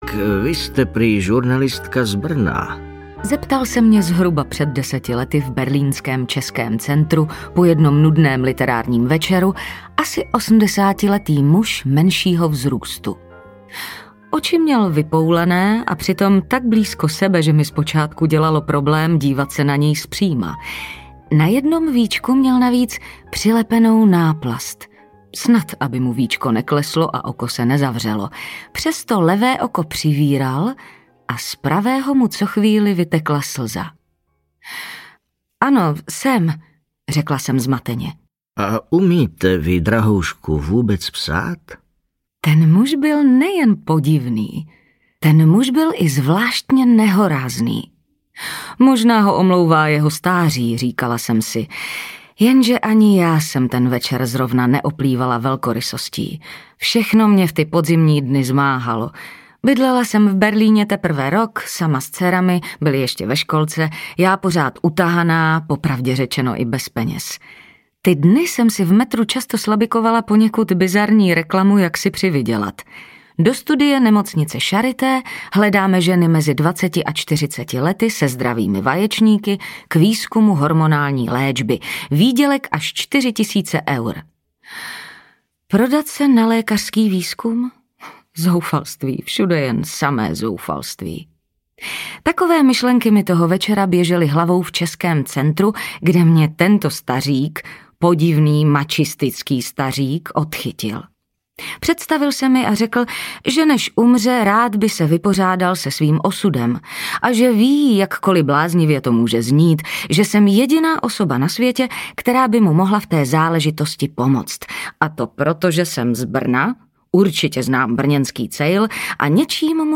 Krvavý Bronx audiokniha
Ukázka z knihy